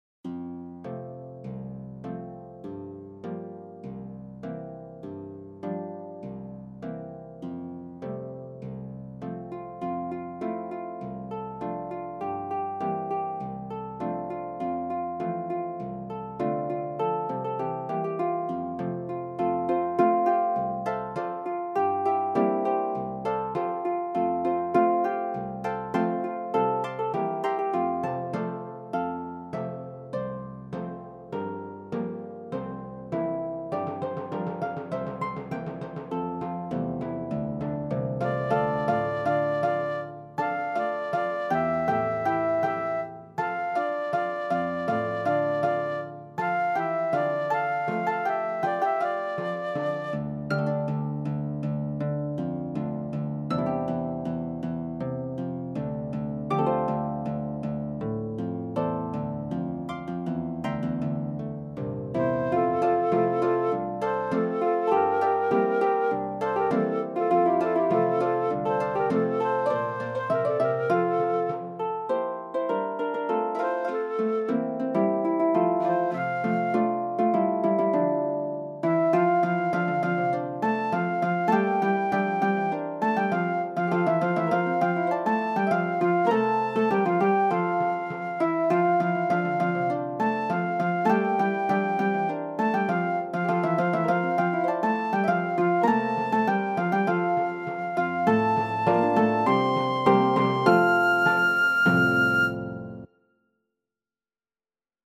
MULTIPLE LEVEL QUARTET, ALL HARPS + WHISTLE.
Harp 4 has octaves in the bass clef.